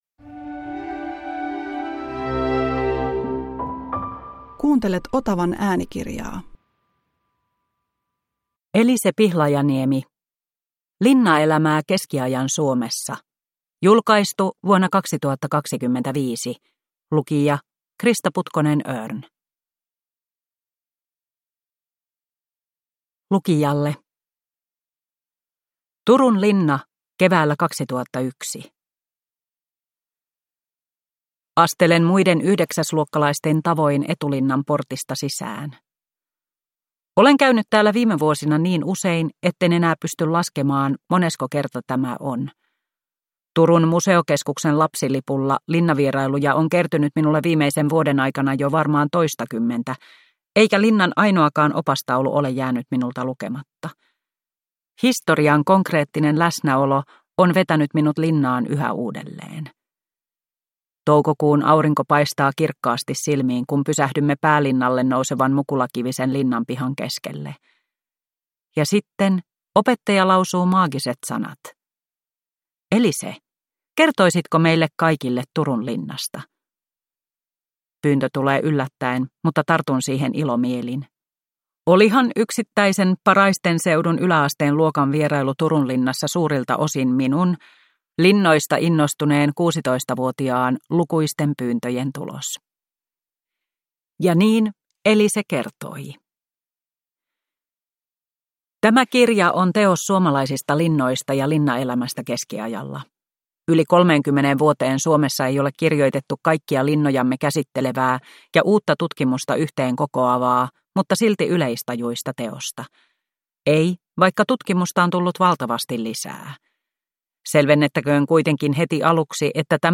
Linnaelämää keskiajan Suomessa – Ljudbok
• Ljudbok